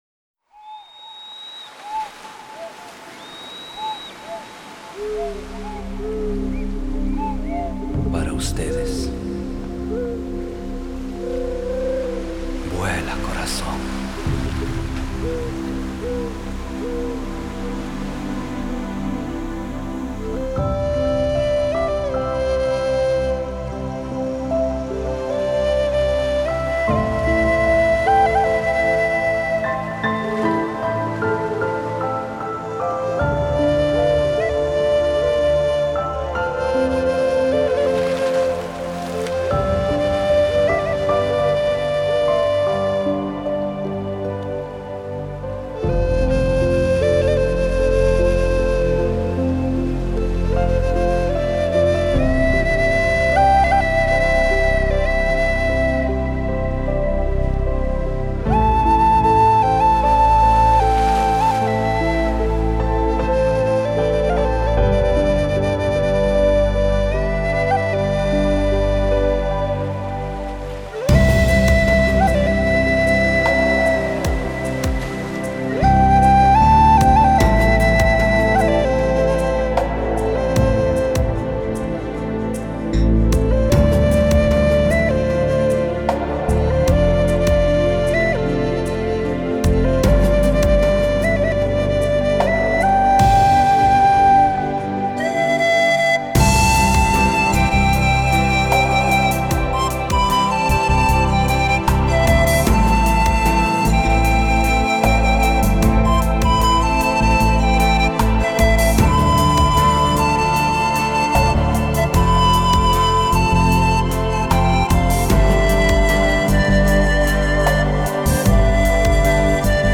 آهنگ بی کلام flying heart